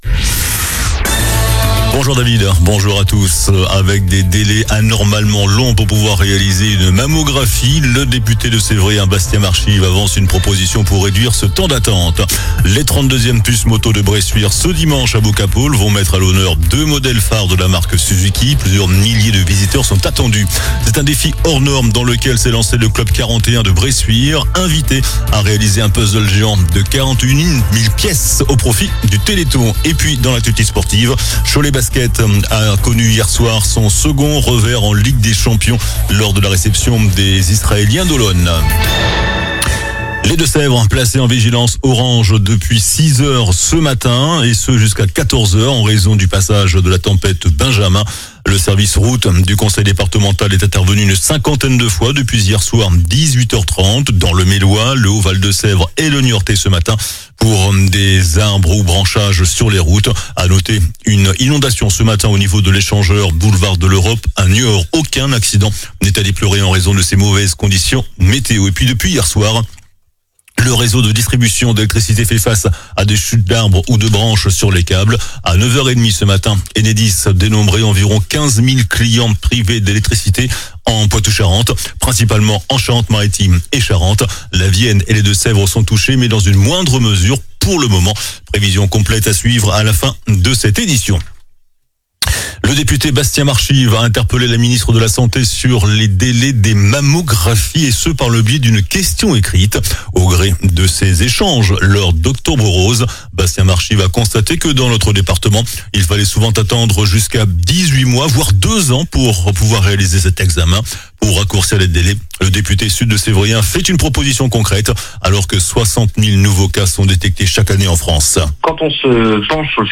JOURNAL DU JEUDI 23 OCTOBRE ( MIDI )